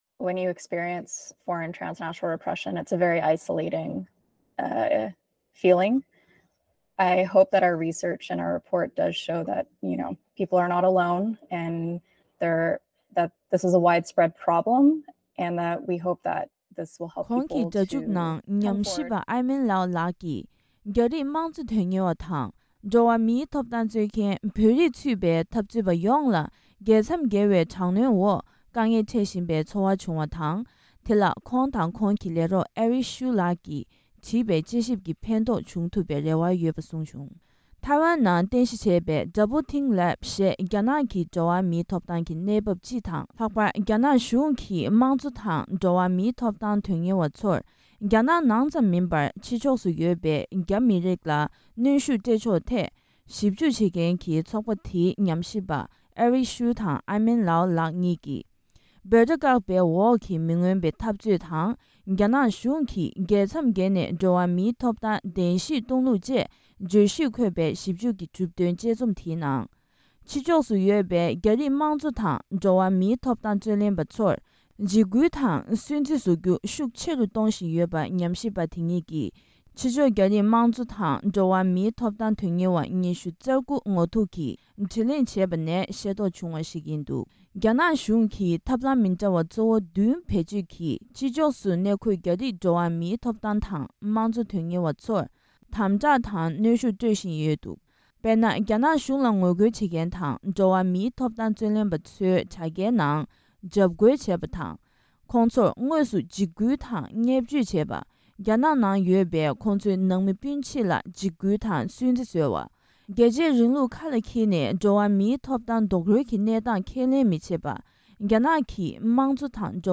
རྒྱ་ནག་གཞུང་ནས་གཞུང་དང་ལྟ་སྤྱོད་མི་མཐུན་མཁན་ཐོག་སྡིག་ར་སྐུལ་བཞིན་པ། ཞིབ་འཇུག་སྙན་ཐོ།
སྒྲ་ལྡན་གསར་འགྱུར།